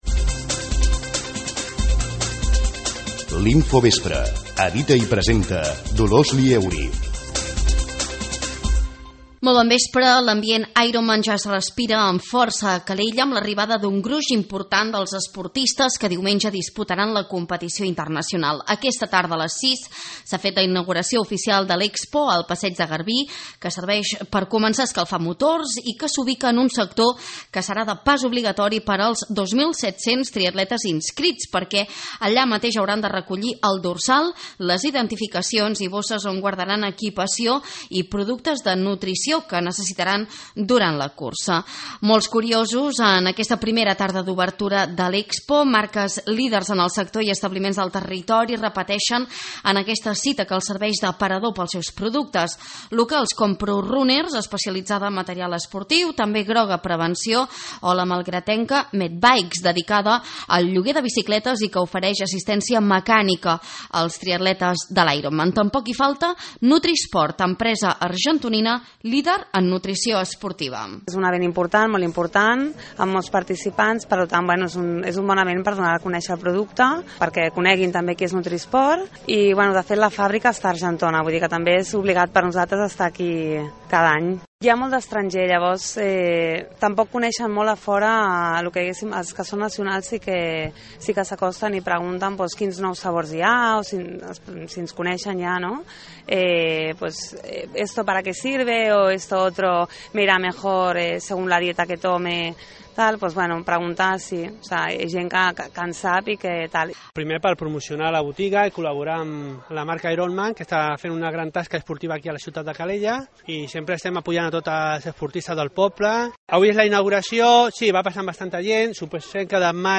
L’Expo de l’Ironman Barcelona ha obert portes aquesta tarda, i ens hi hem acostat per parlar amb els expositors que promocionen els seus productes i amb esportistes, força tranquils a tres dies per l’inici de la competició. També recollim les declaracions del tinent d’Alcaldia de Ciutadania i responsable d’Esports, Xavier Arnijas, valorant l’alta participació internacional en aquesta edició.